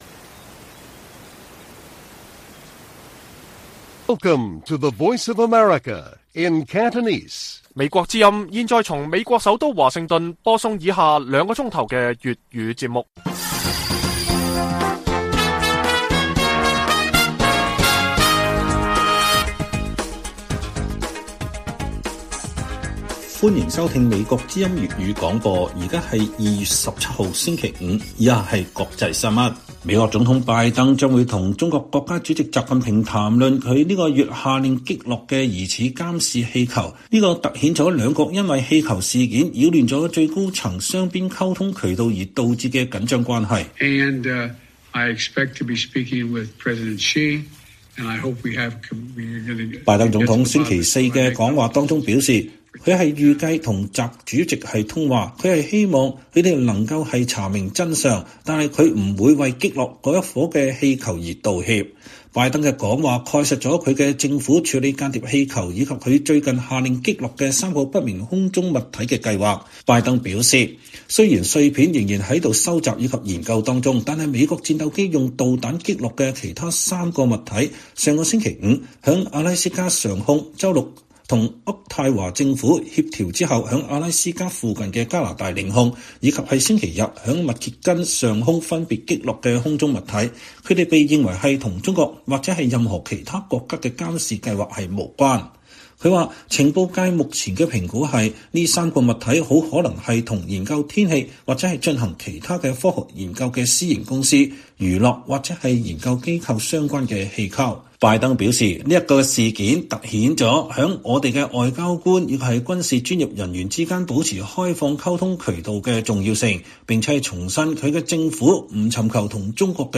粵語新聞 晚上9-10點：拜登將與習近平討論中國間諜氣球